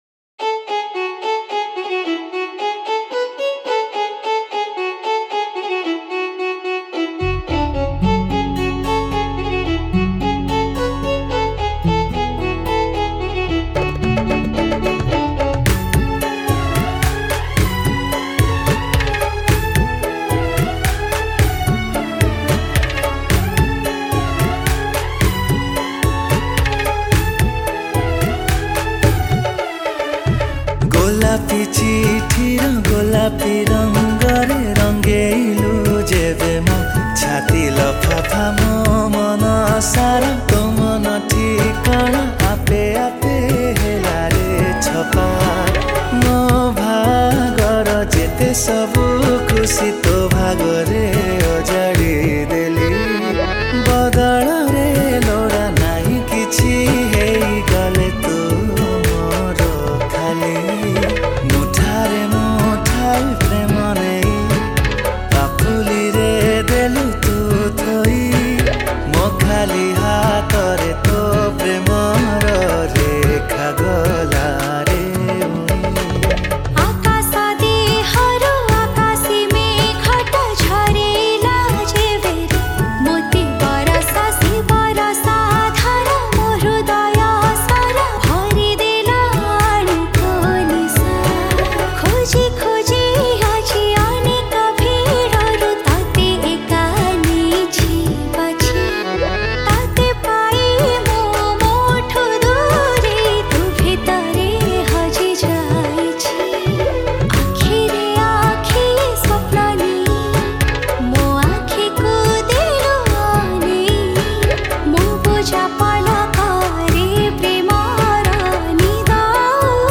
Live Keys & Guitar